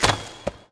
archer_powershot_end.wav